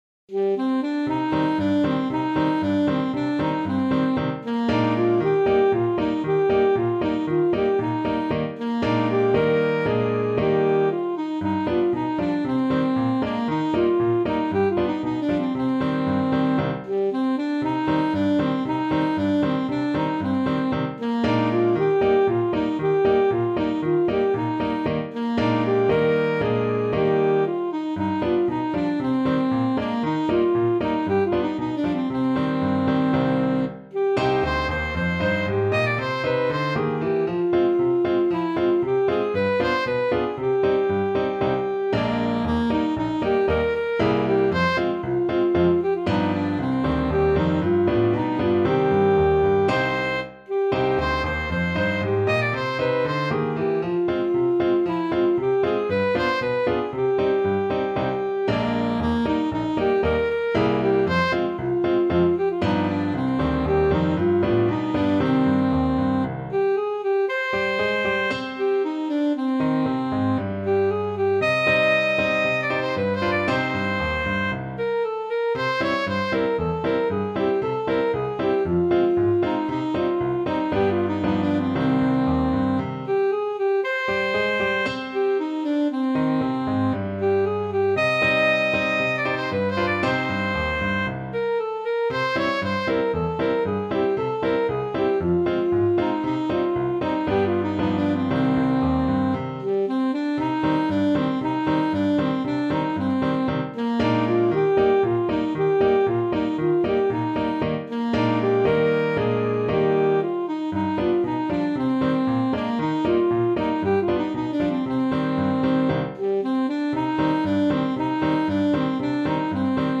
Alto Saxophone
2/4 (View more 2/4 Music)
Allegro =c.116 (View more music marked Allegro)
Traditional (View more Traditional Saxophone Music)